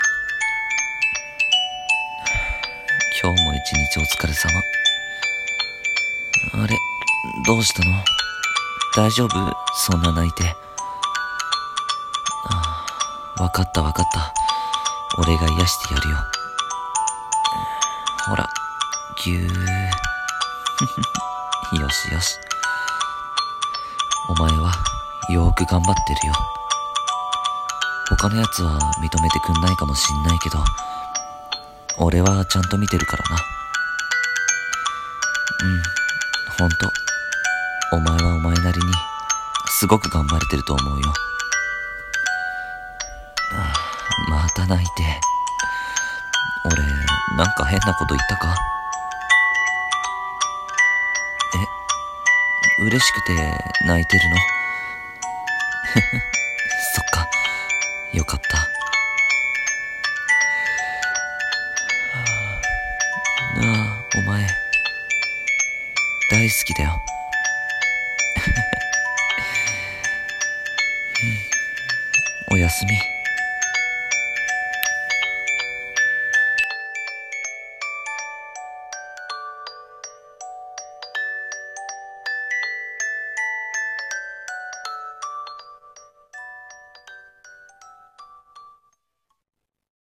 1人声劇「癒しの添い寝彼氏